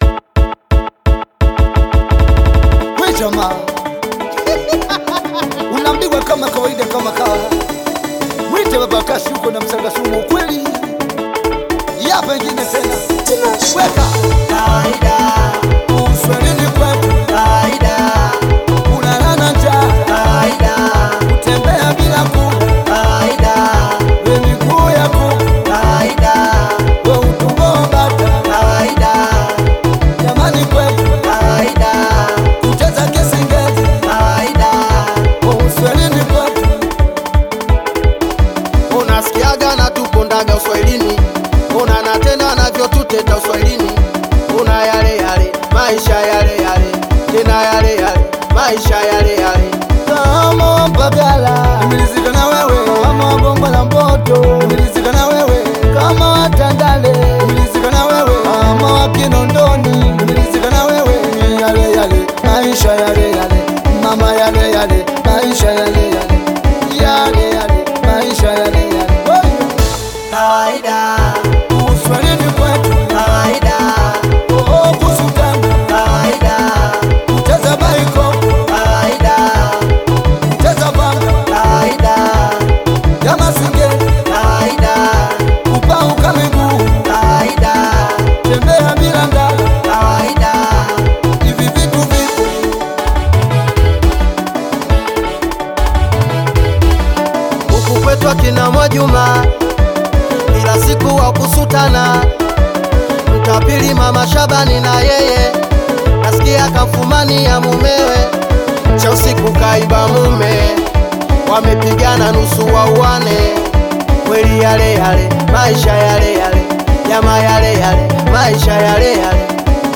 high-energy Singeli/Bongo-Flava anthem